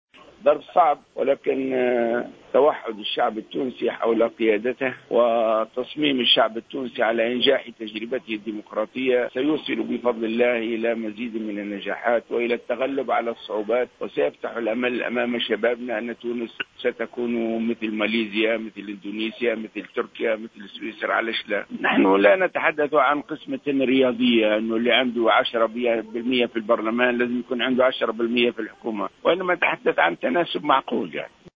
قال راشد الغنوشي رئيس حركة النهضة في تصريح للجوهرة أف أم خلال حضوره بولاية باجة اليوم الأحد 14 أوت 2016 للاحتفال بيوم العلم إن الحركة حريصة على أن يكون تمثيلها في الحكومة مناسبا لحجمها دون أن يعني ذلك القيام بعملية قسمة حسابية مضبوطة"وفق تعبيره.